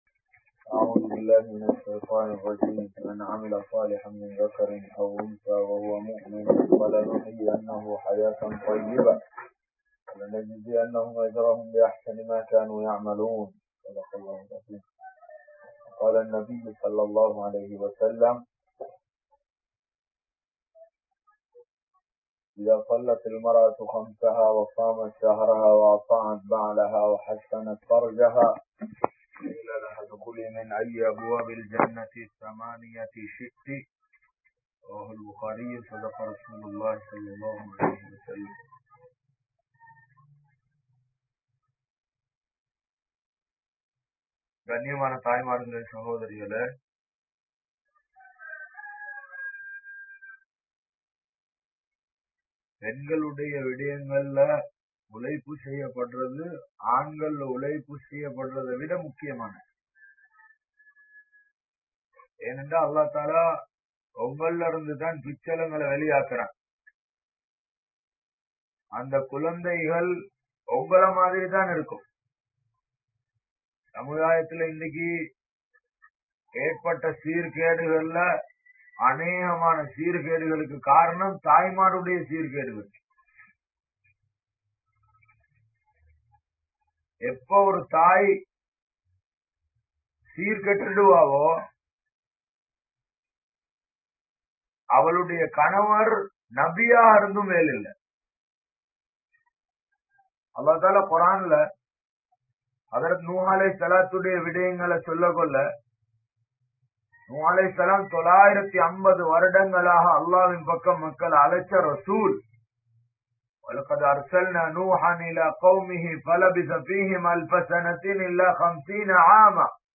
Kulanthai Valarpu (குழந்தை வளர்ப்பு) | Audio Bayans | All Ceylon Muslim Youth Community | Addalaichenai